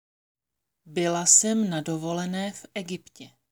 Tady si můžete poslechnout nahrávku výslovnosti Byla jsem na dovolené v Egyptě.